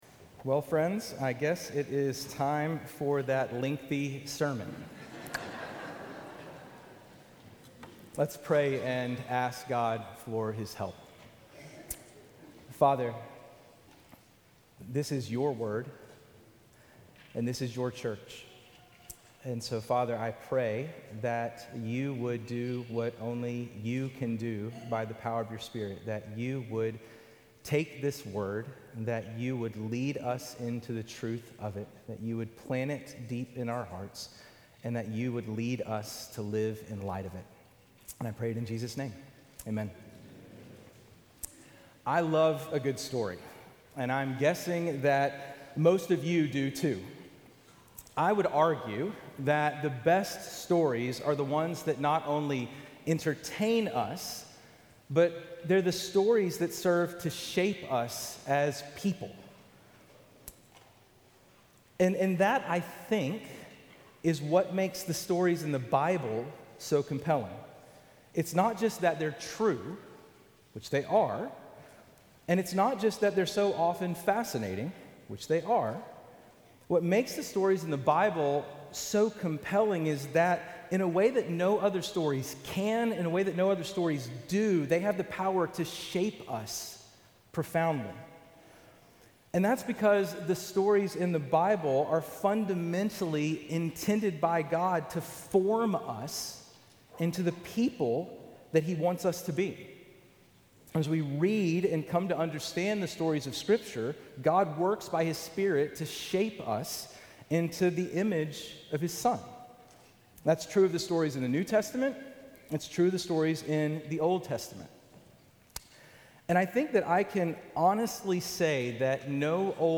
850 Mt Vernon Hwy NW Sandy Springs, GA 30327 GET DIRECTIONS